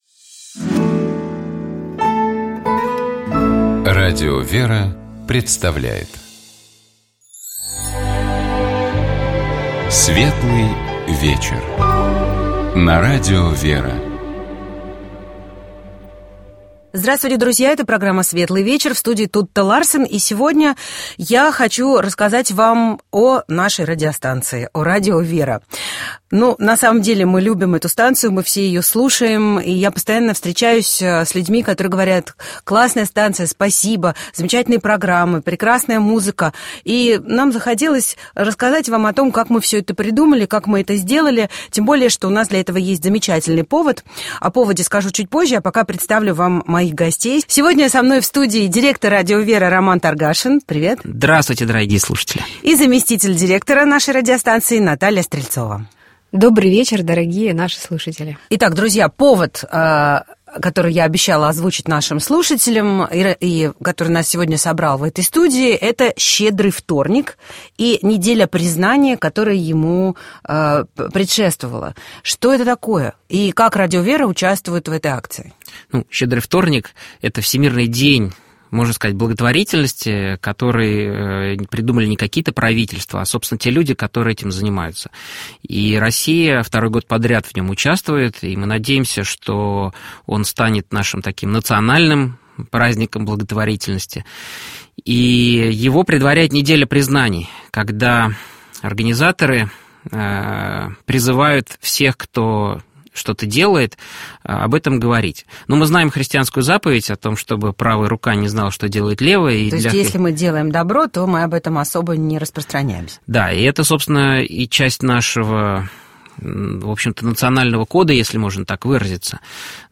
Тутта Ларсен беседовала с руководителями радиостанции о том, как создавалось Радио ВЕРА, как существует и развивается сейчас и о том, какая поддержка требуется радиостанции.